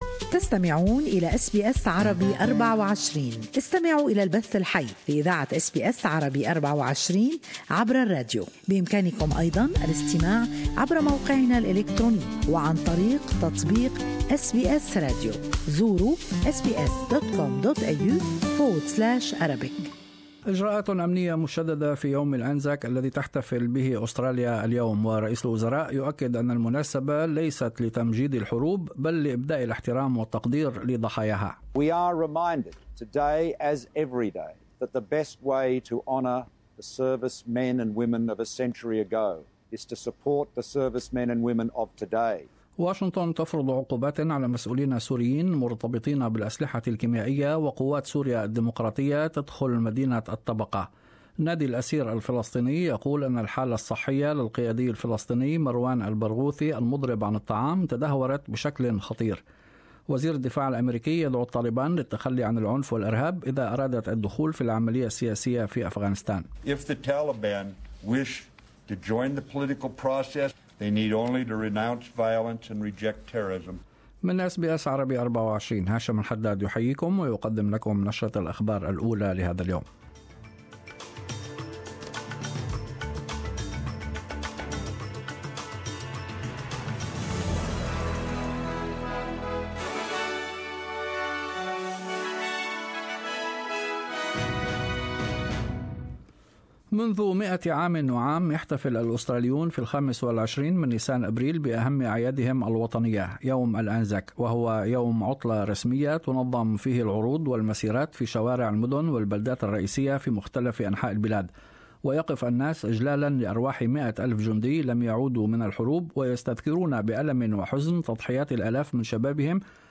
Australian and World news in the morning news bulletin.